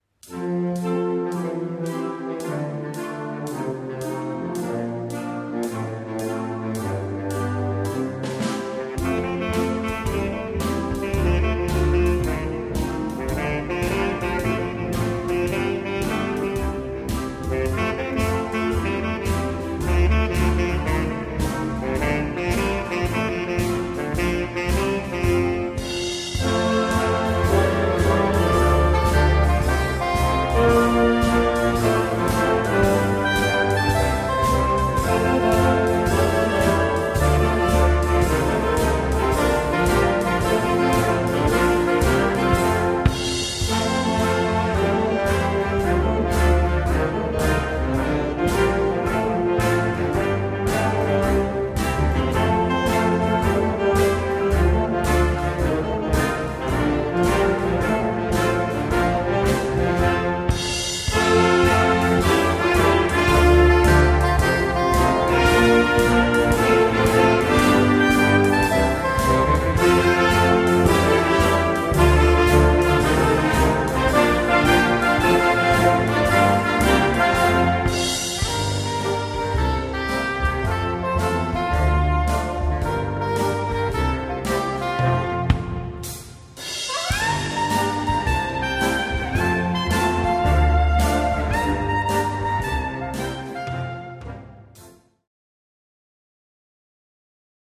Partitions pour orchestre d'harmonie, ou - fanfare.
• View File Orchestre d'Harmonie
• View File Orchestre de Fanfare